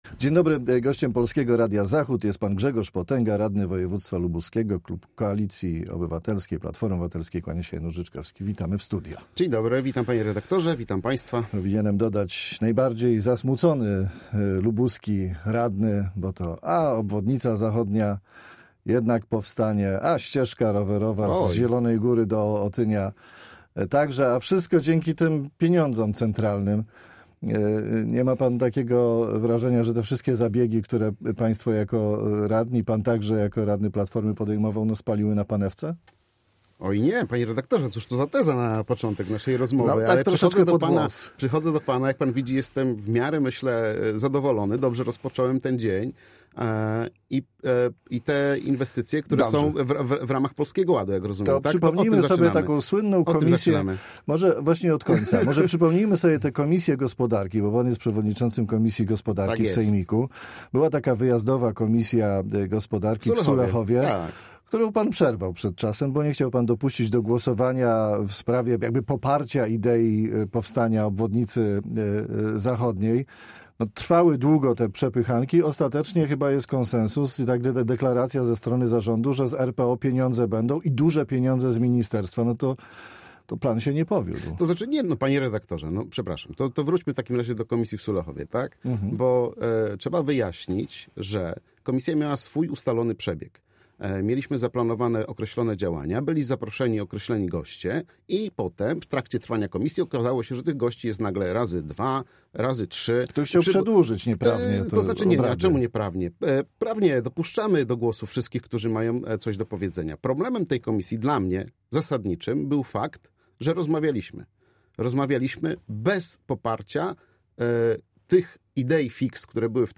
Grzegorz Potęga, radny województwa lubuskiego (KO)